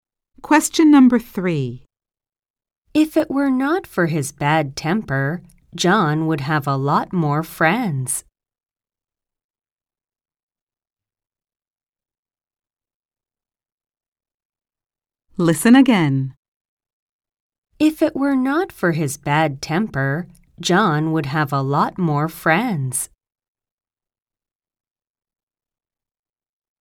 〇アメリカ英語に加えて、イギリス英語、アジア英語の話者の音声も収録しています。
ノーマル・スピード音声   ハイ・スピード音声